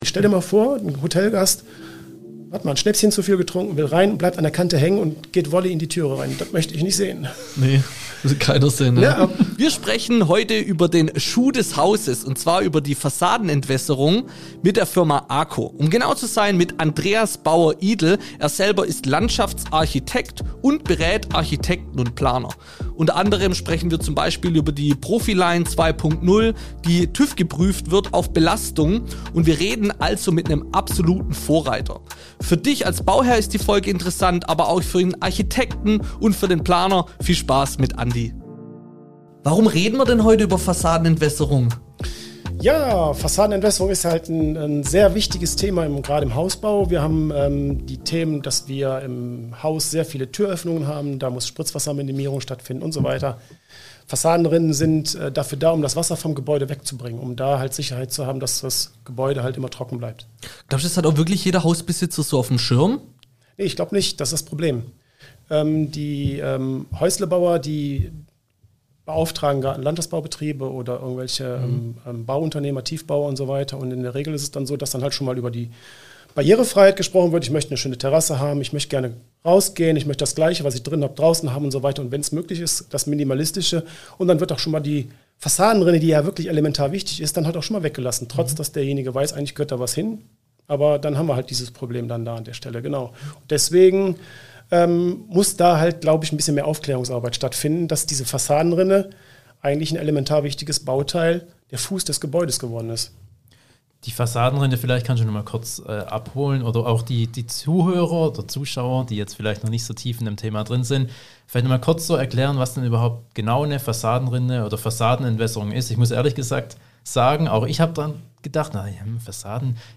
Fassadenentwässerung - Im Gespräch mit ACO ~ Bau Podcast - der Höfliche & der BAUstein Podcast